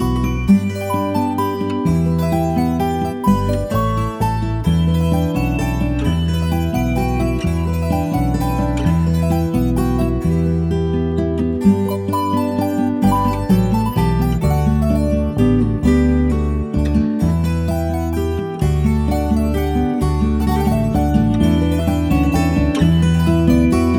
No Vocals At All Pop (1960s) 3:08 Buy £1.50